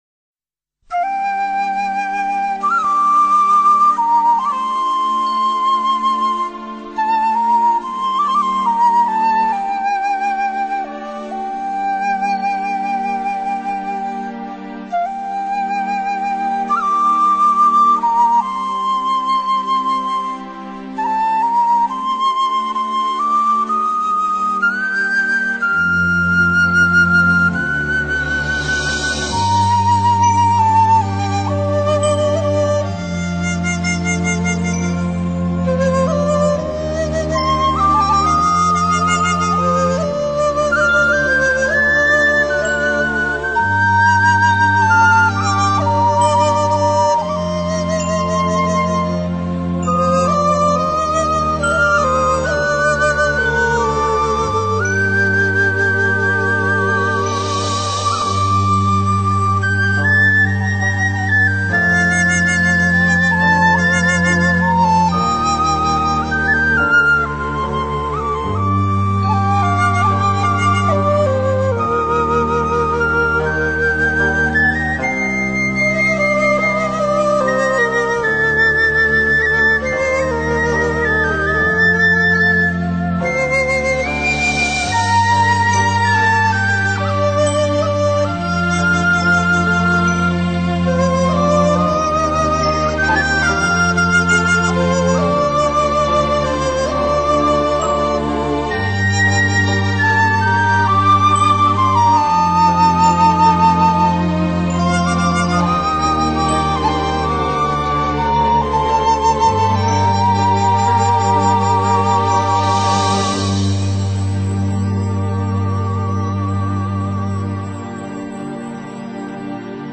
古典民乐, 经典配乐 你是第8229个围观者 0条评论 供稿者： 标签：, ,
整个曲调感觉悠扬、凄冷幽怨，真正的和剧情非常的贴切，剧中深宫中的凄冷都在这音乐里面体现得淋漓尽致。